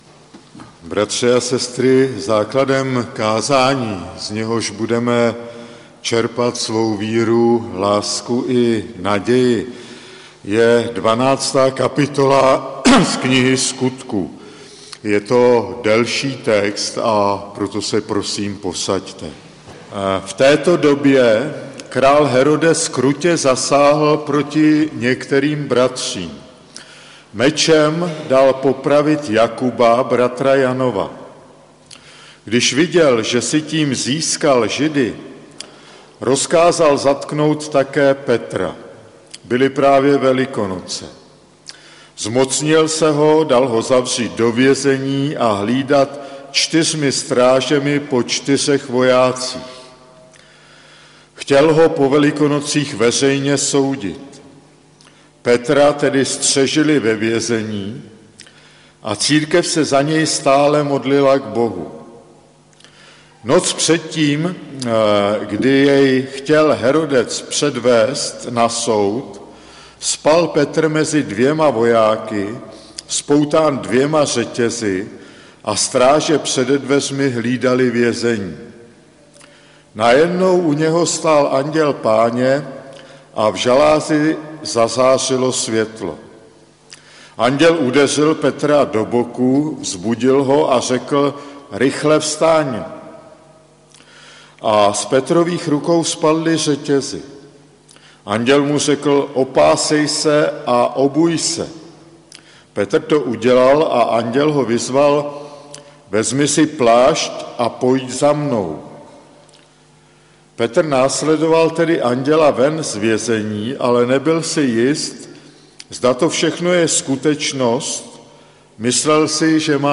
Kázání 5.11.2017 | Farní sbor ČCE Nové Město na Moravě